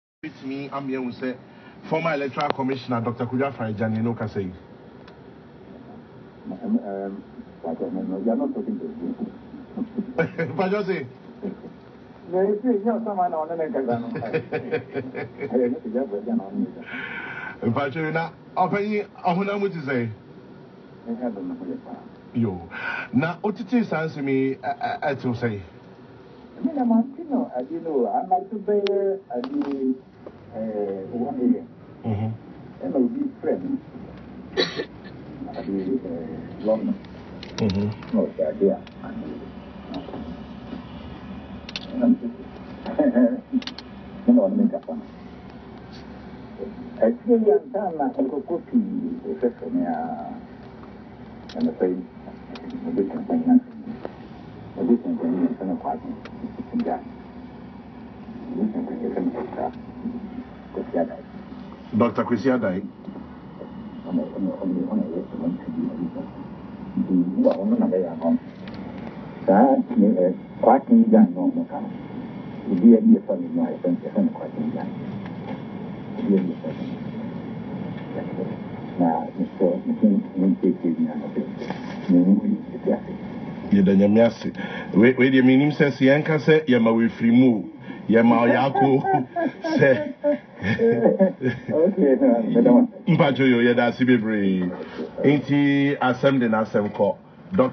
Speaking to Peace FM Monday morning he said “by God’s grace I am good. You are not talking to a ghost.”